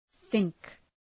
Προφορά
{ɵıŋk}